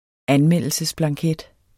Udtale [ ˈanˌmεlˀəlsəs- ]